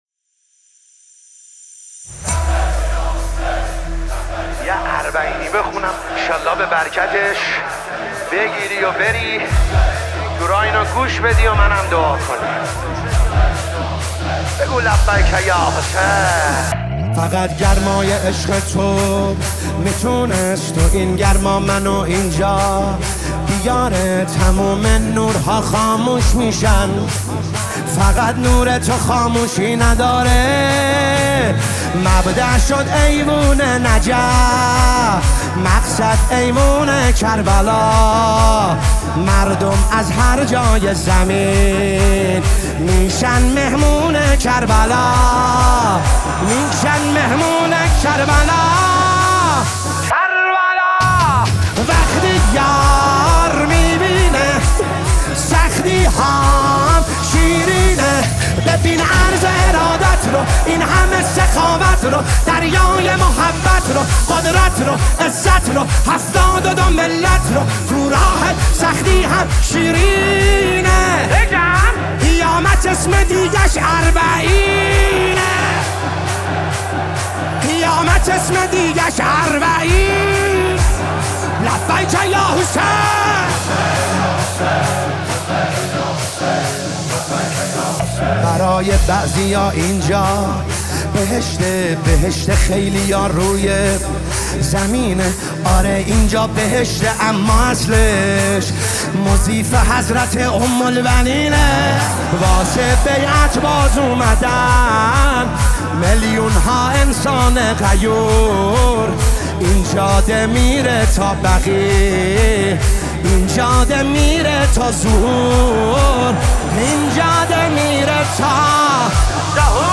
نماهنگ مذهبی
صوت مداحی